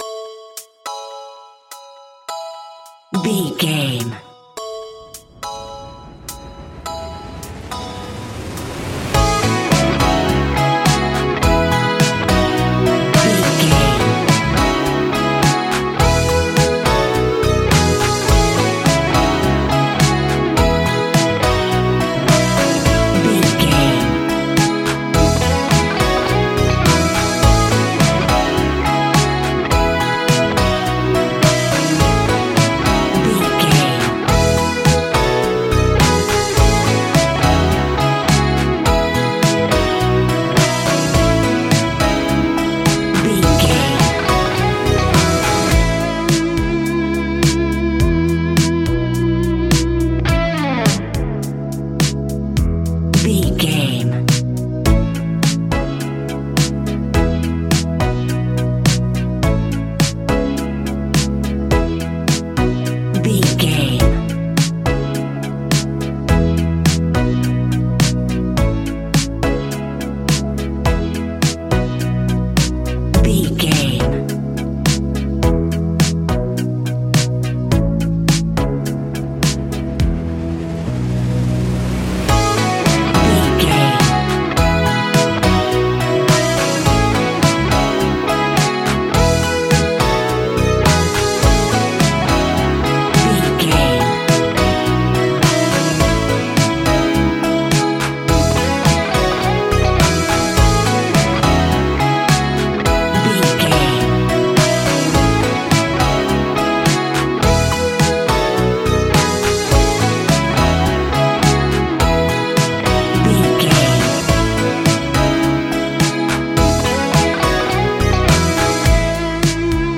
Ionian/Major
ambient
electronic
new age
chill out
downtempo
synth
pads
drone